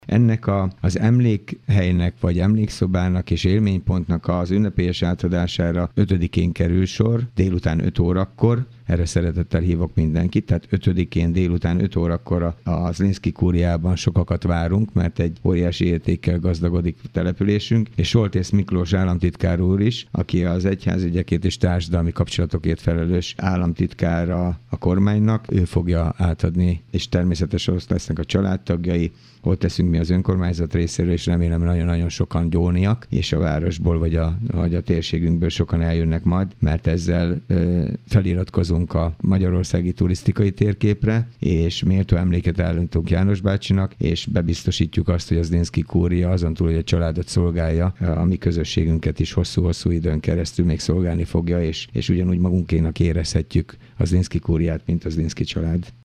Kőszegi Zoltán polgármestert hallják.